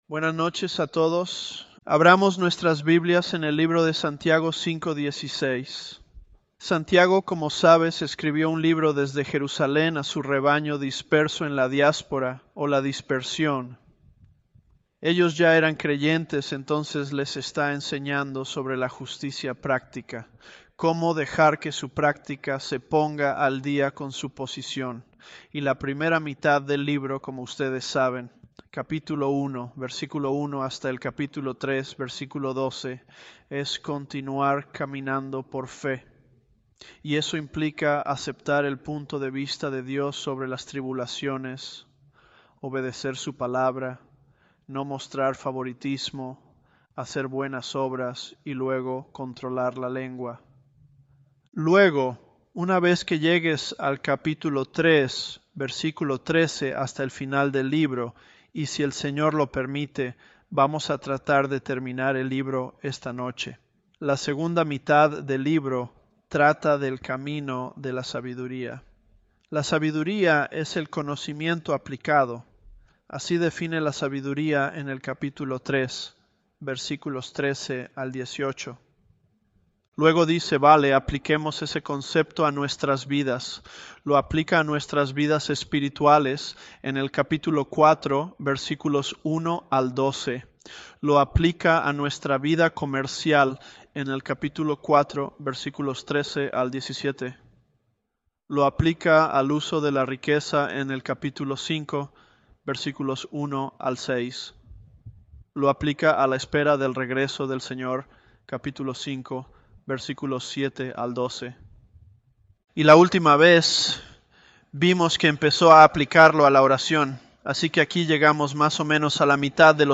ElevenLabs_James029b.mp3